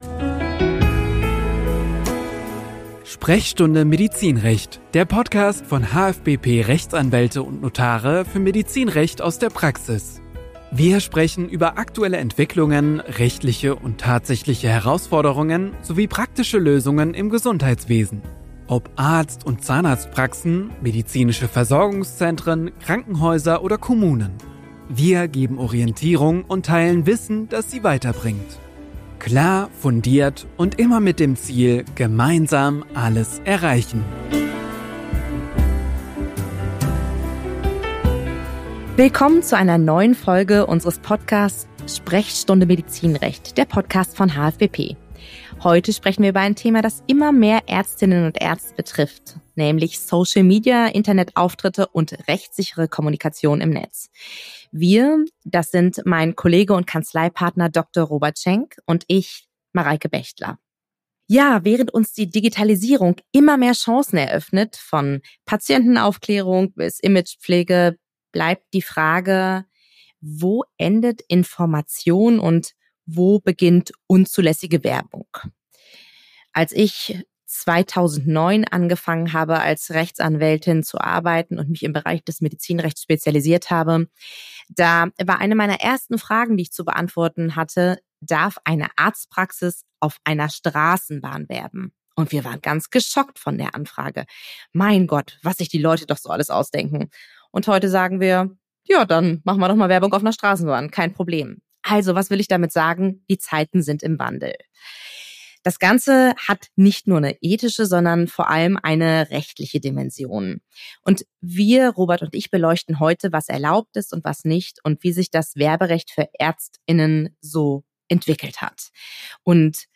Dazu geben die beiden Fachanwälte wichtige Informationen.